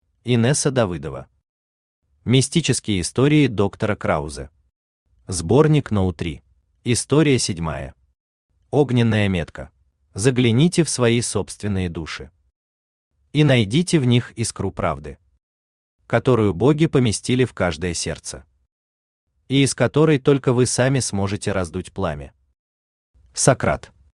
Аудиокнига Мистические истории доктора Краузе. Сборник №3 | Библиотека аудиокниг
Сборник №3 Автор Инесса Давыдова Читает аудиокнигу Авточтец ЛитРес.